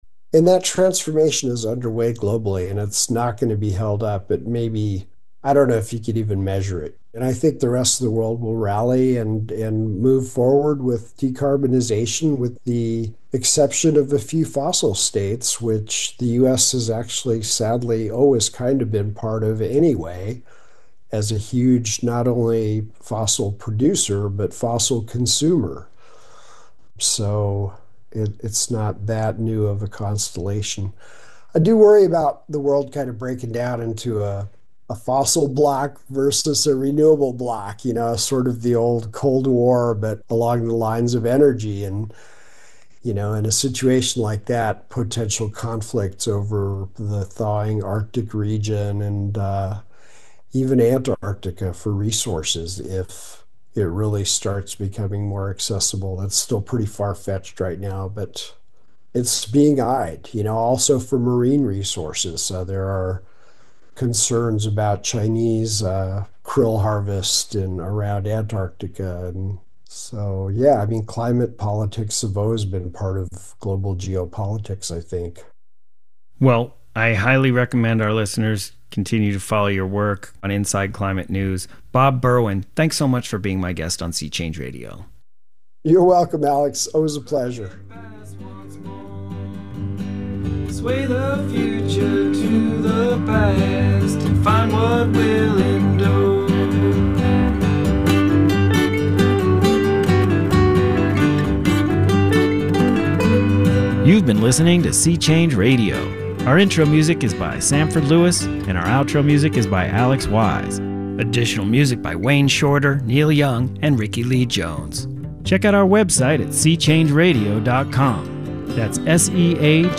interviews painter